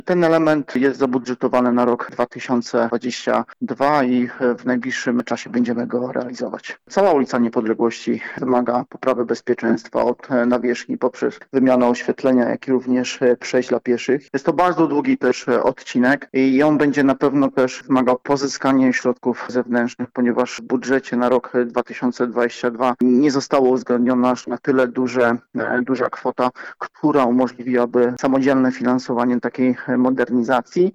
Mówi Grzegorz Rybaczuk, radny miasta Stargard.